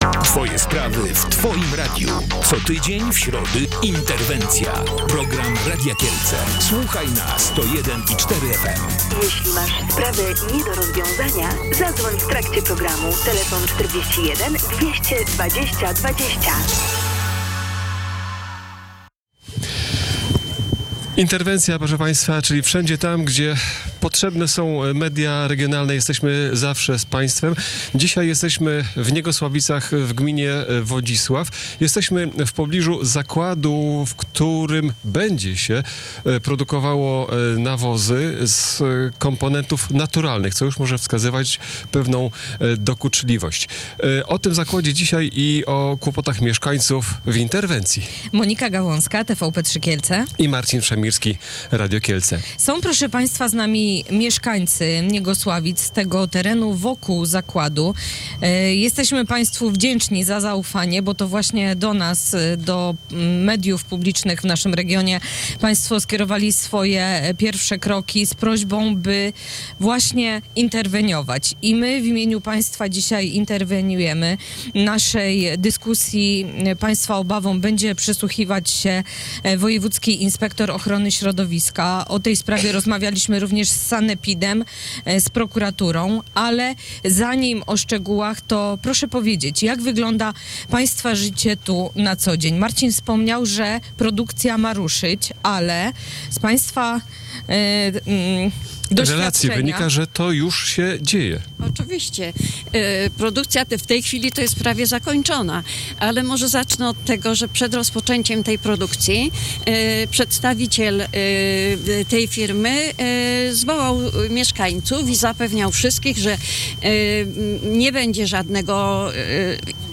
O uciążliwym sąsiedztwie rozmawialiśmy w środę (9 kwietnia) w programie Interwencja.